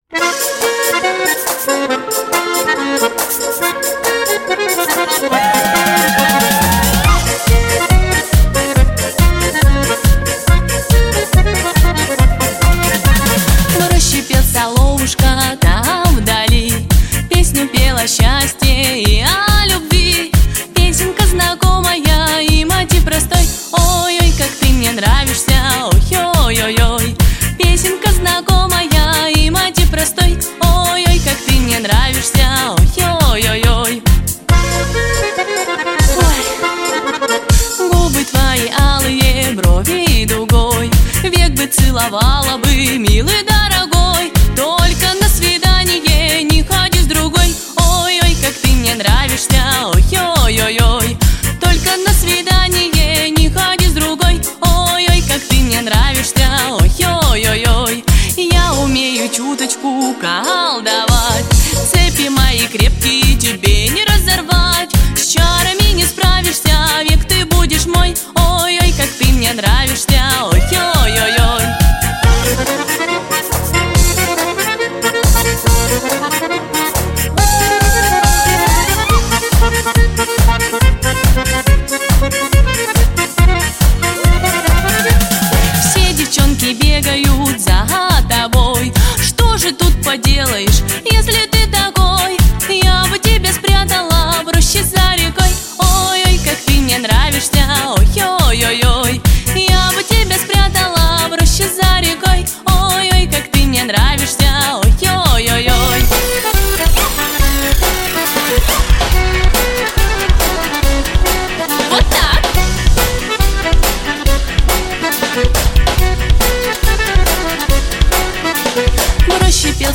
• Качество: Хорошее
• Категория: Детские песни
народный мотив